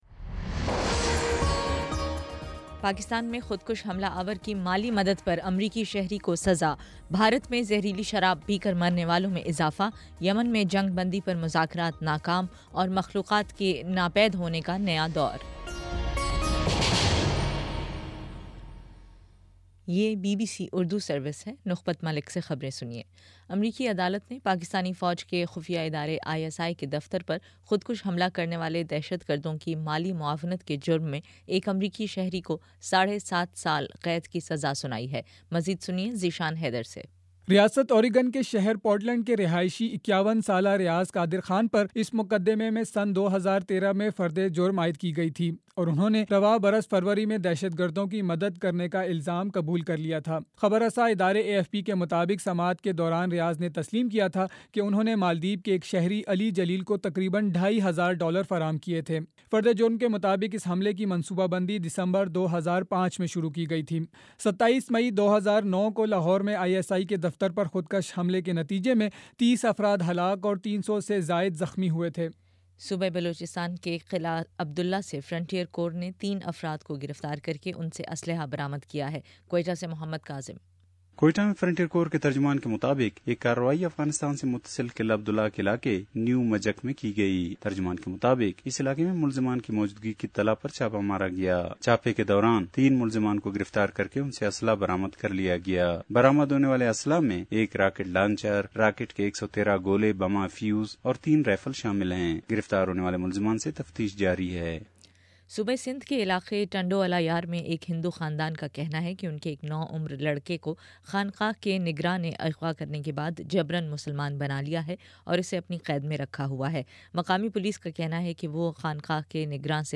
جون 20: شام چھ بجے کا نیوز بُلیٹن